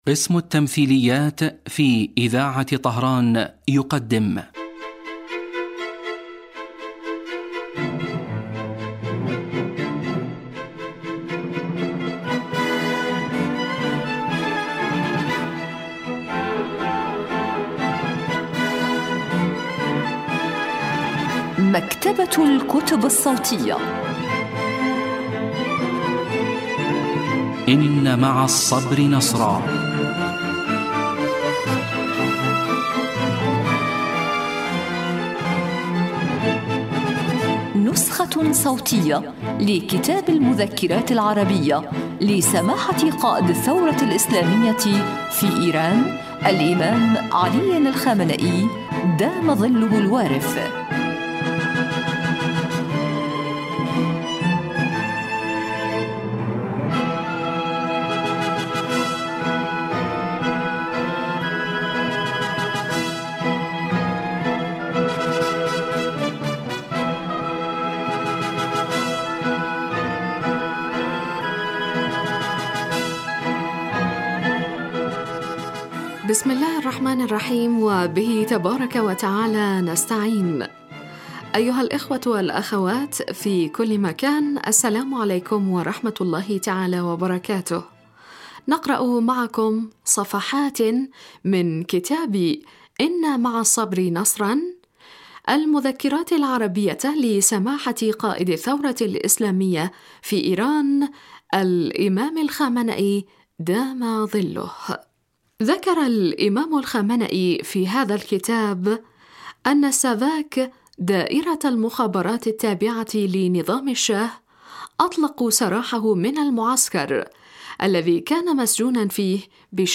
إذاعة طهران- إن مع الصبر نصرا: نسخة صوتية لكتاب المذكرات العربية لقائد الثورة الإسلامية الإمام الخامنئي (دام ظله).